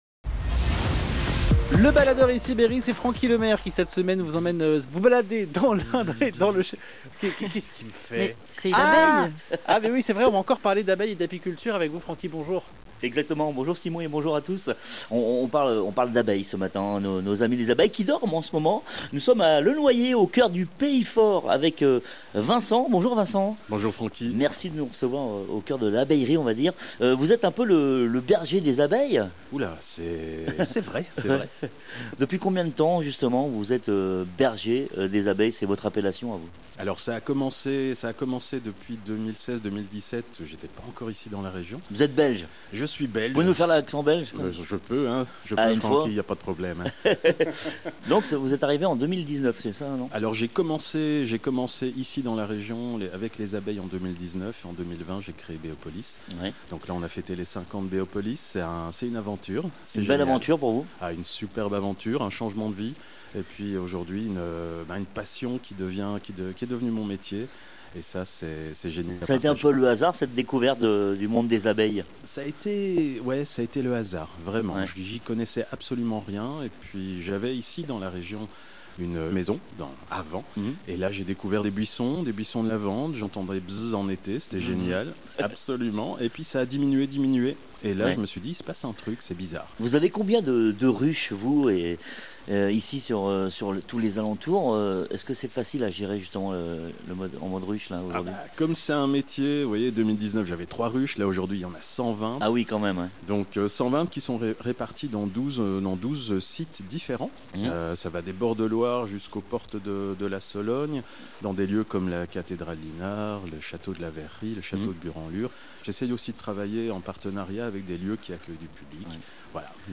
votre berger des abeilles Ecoutez le reportage https